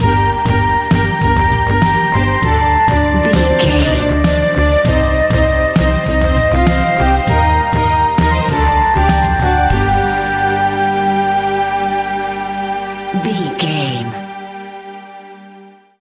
Aeolian/Minor
World Music
percussion
congas
bongos
kora
djembe
kalimba
marimba